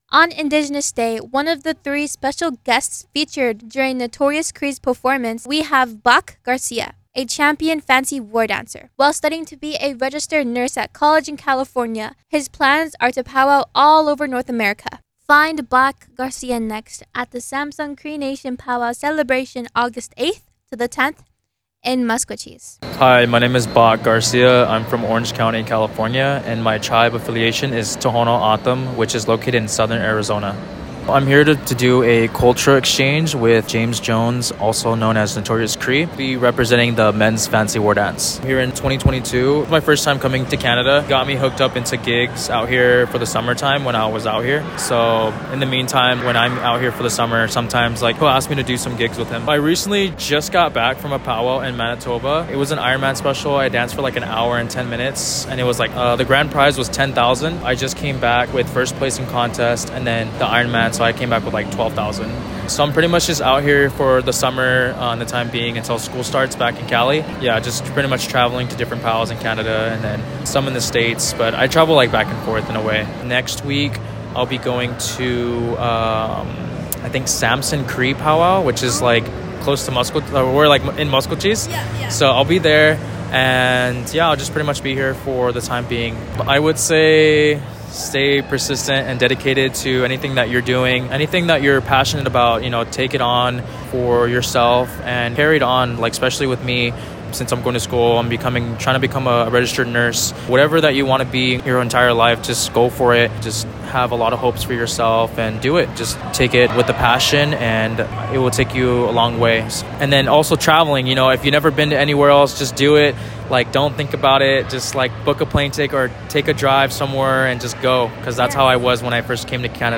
Additionally, here is an interview with one of the surprise guests that performed with Notorious Cree…